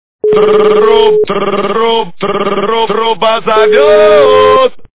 » Звуки » Люди фразы » Голос пионервожатого - Тр-тр-труба-а зове-е-е-т!
При прослушивании Голос пионервожатого - Тр-тр-труба-а зове-е-е-т! качество понижено и присутствуют гудки.